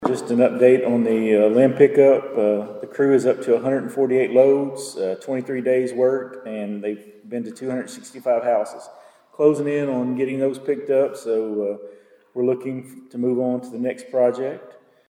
At Monday night’s meeting, the Princeton City Council received updates on local issues, including trash and limb pickups and a roof collapse at a downtown building.
In the mayor’s report, Thomas mentioned that Public Works crews have collected nearly 150 loads of limbs.